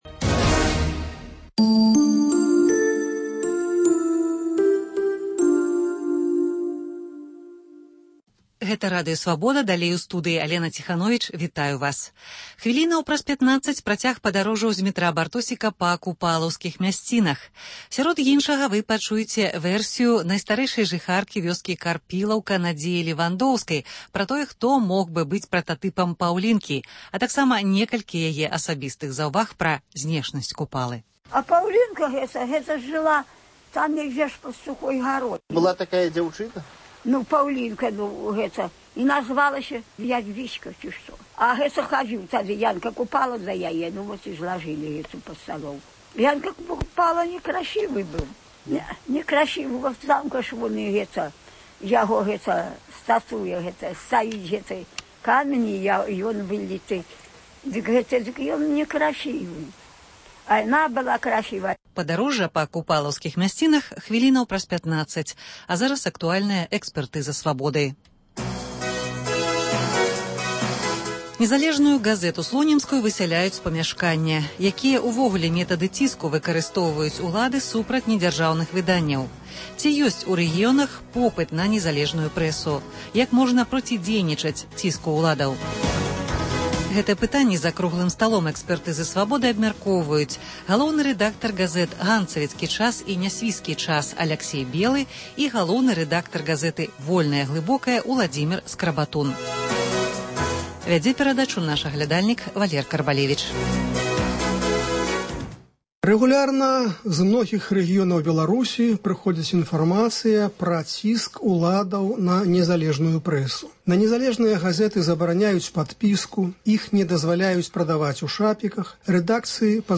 Госьць у студыі адказвае на лісты, званкі, СМСпаведамленьні